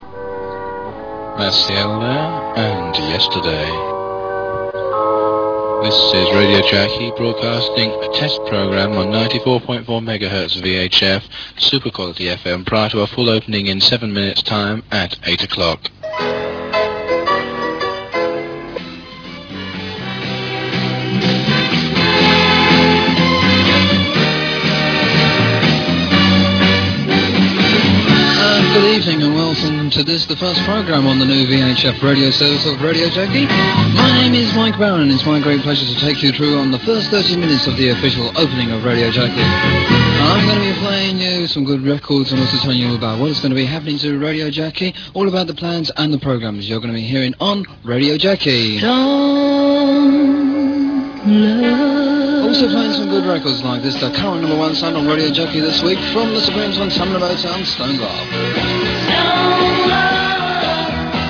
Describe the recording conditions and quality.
The first FM Broadcast